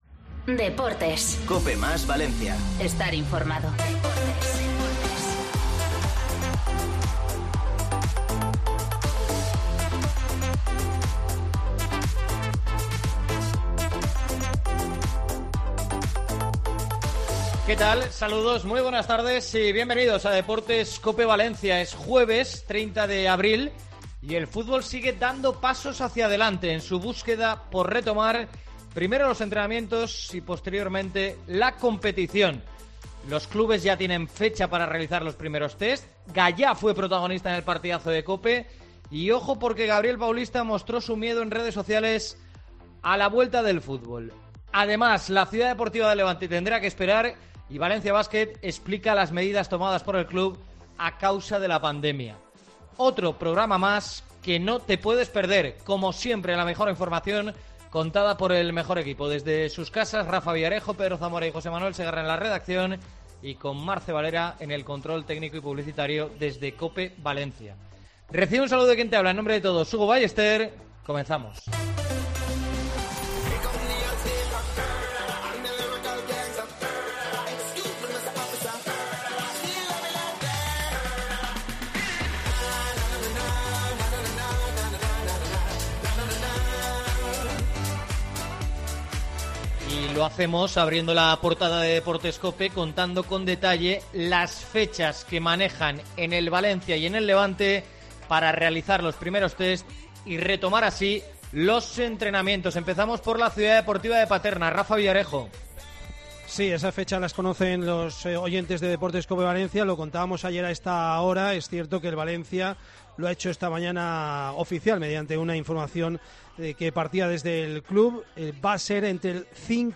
AUDIO: La entrevista a GAYÀ en EL PARTIDAZO hablando del virus, la vuelta a los entrenamientos, el miedo y muchos otros temas, la reacción de...